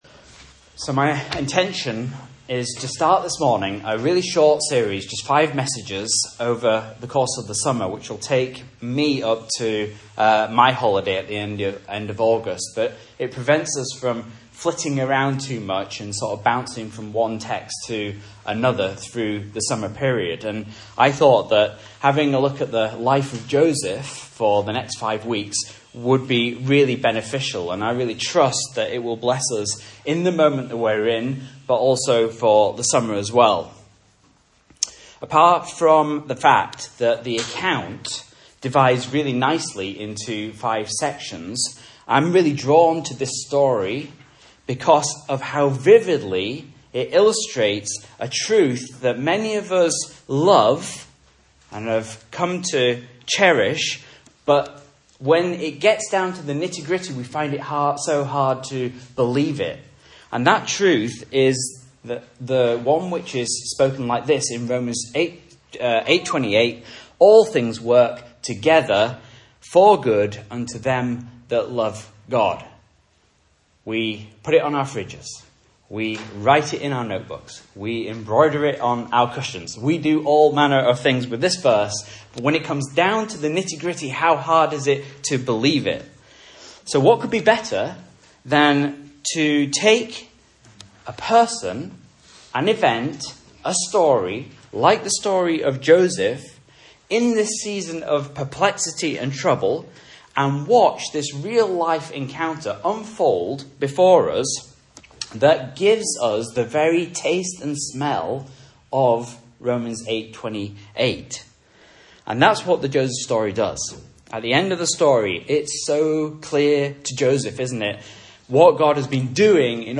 Message Scripture: Genesis 37 | Listen